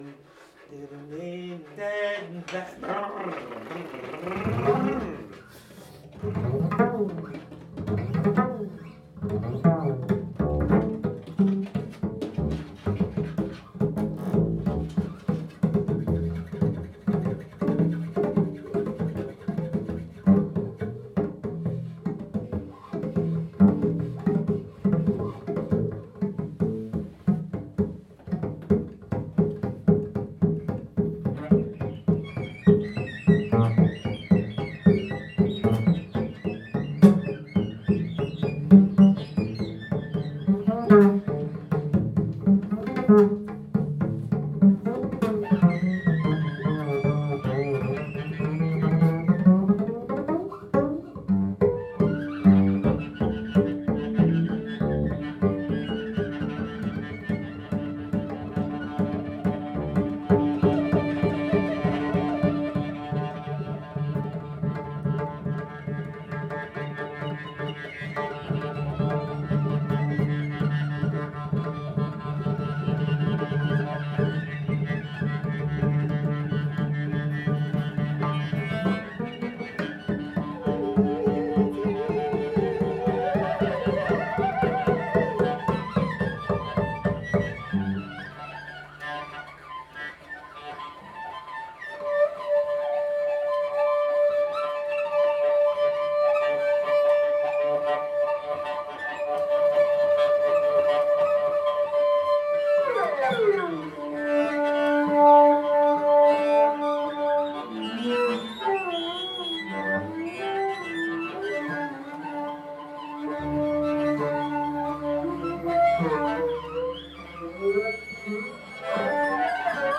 DUO CONTREBASSE-VLLE
au Salon de musique des Quatre Vents